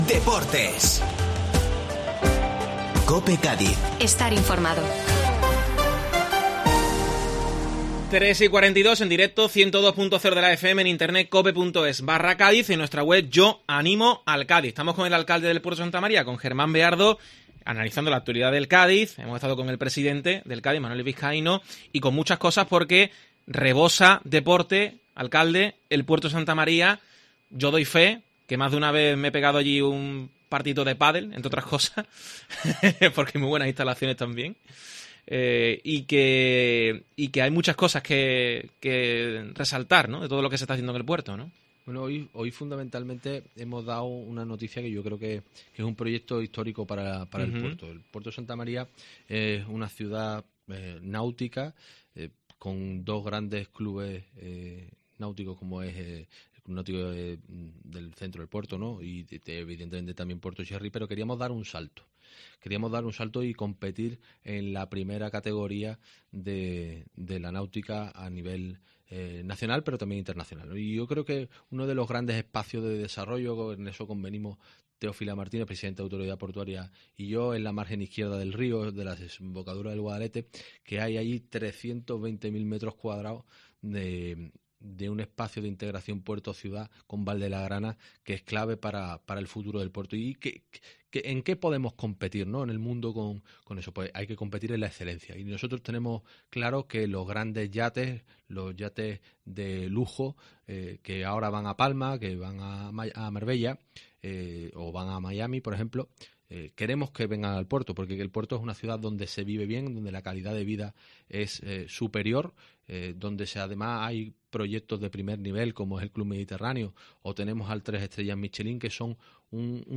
Germán Beardo, alcalde de El Puerto, en COPE
Escucha la entrevista a Germán Beardo en COPE Cádiz También te puede interesar: El Puerto de Santa María tendrá una calle 'Cádiz CF'